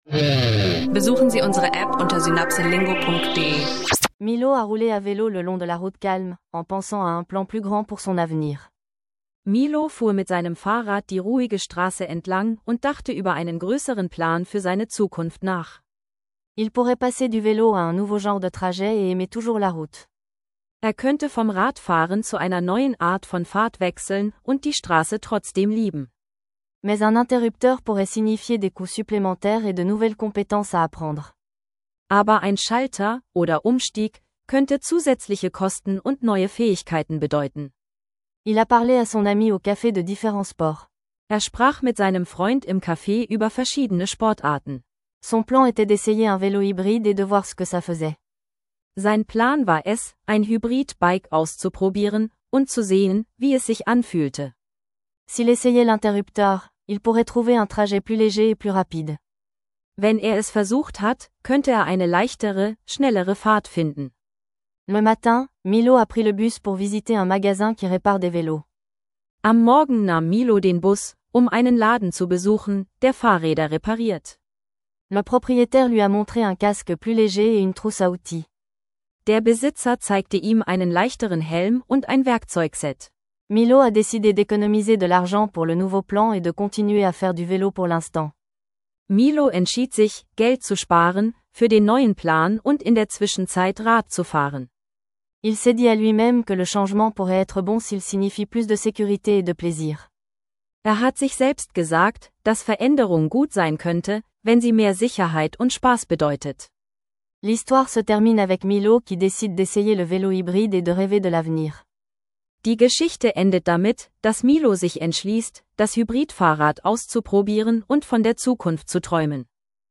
Lerne Französisch mit einer erzählerischen Lektion über